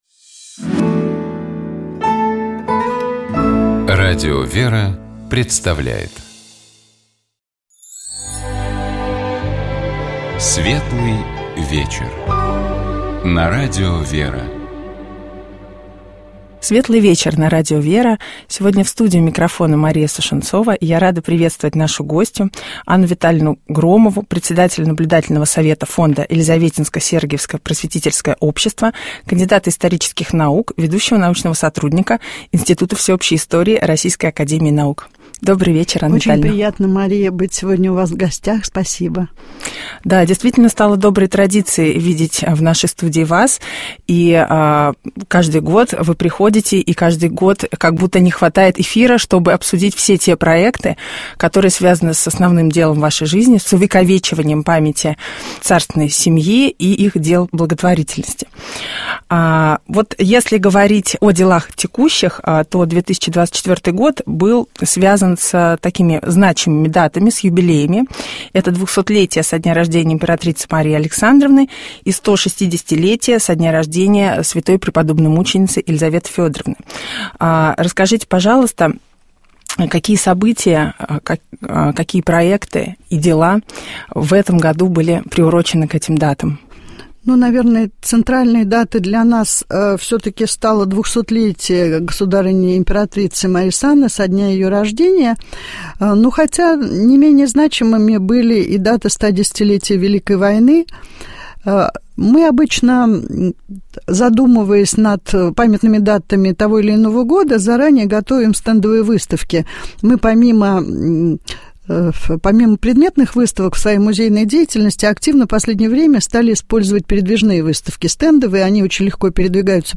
Текст Марии Крыловой читает Тутта Ларсен.